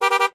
honk2.ogg